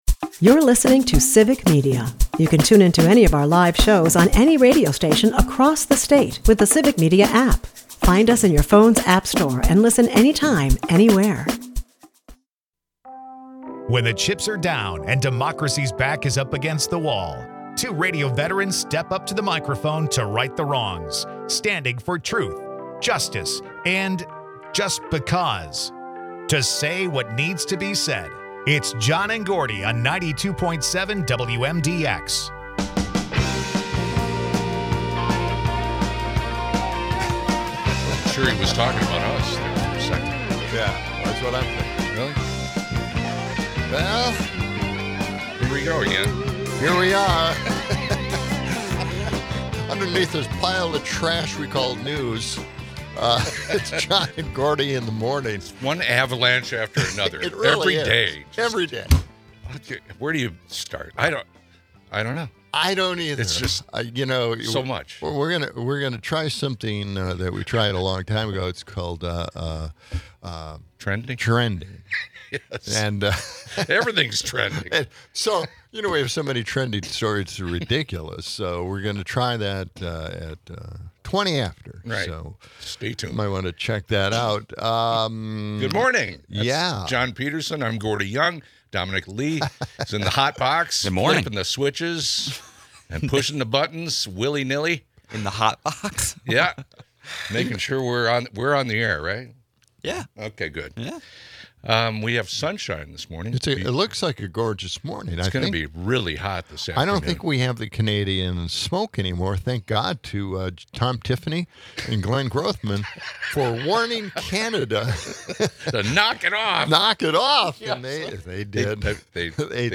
On a serious note, they tackle the controversial blocking of the Epstein file releases and the grim humanitarian crisis in Gaza. They talk with texters about spork redesigns and ponder the implications of white nationalism.